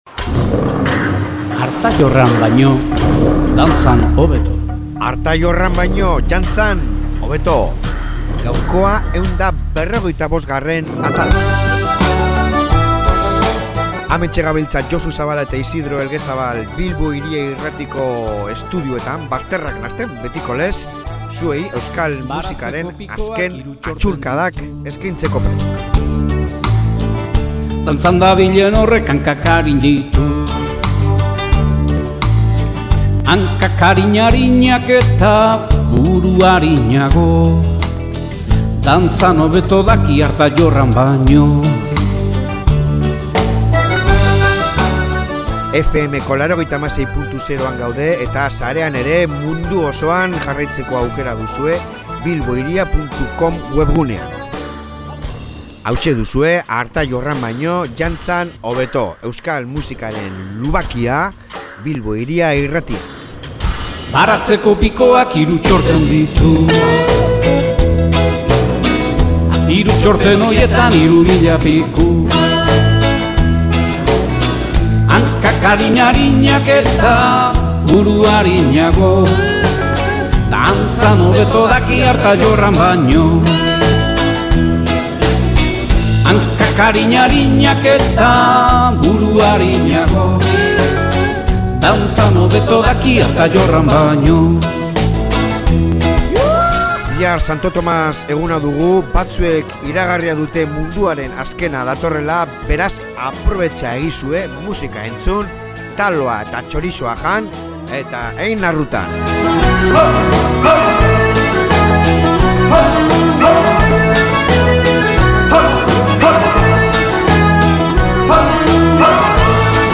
.... Durangoko Azokan izan ginen